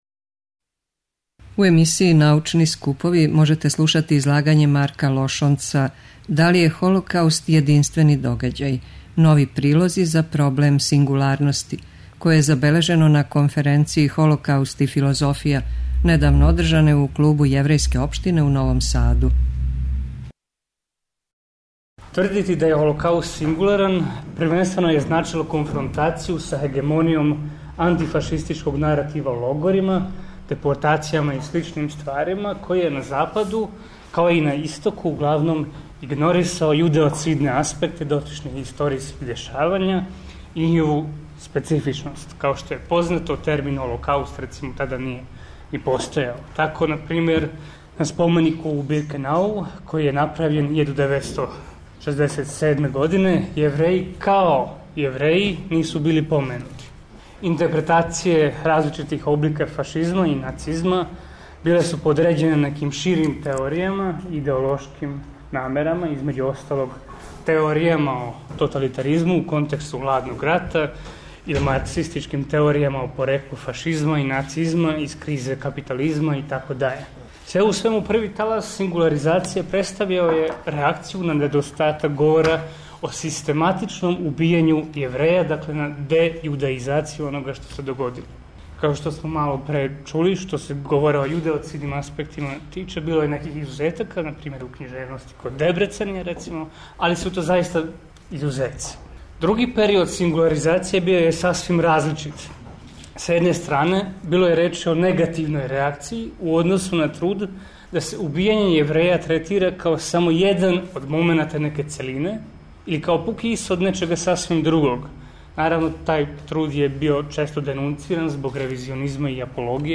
Научни скупови
преузми : 7.80 MB Трибине и Научни скупови Autor: Редакција Преносимо излагања са научних конференција и трибина.